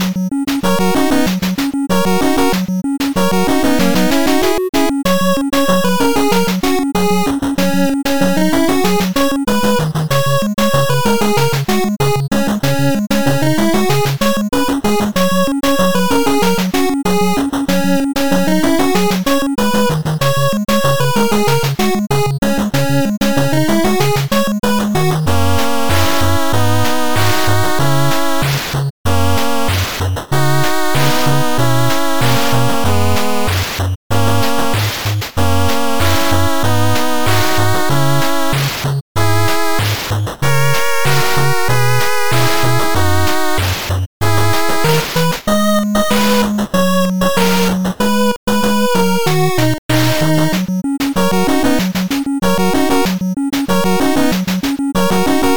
8bit music for action game.